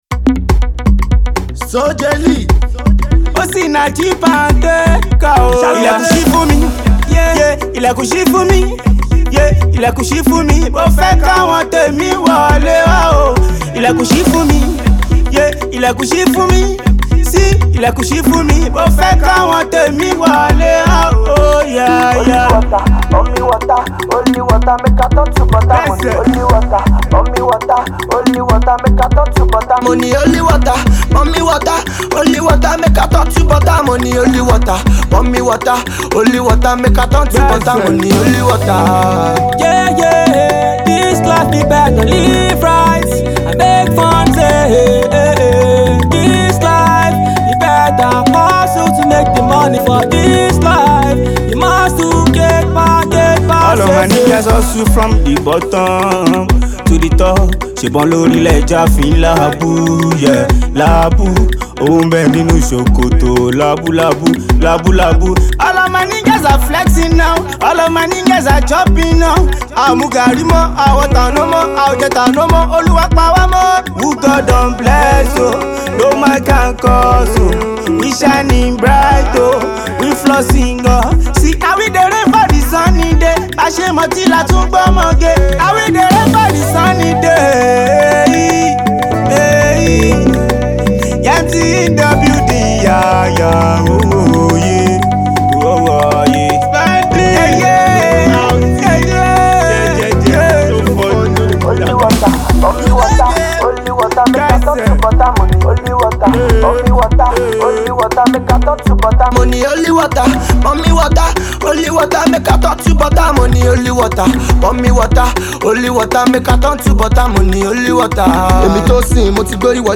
soulful song laced with Afro beat tune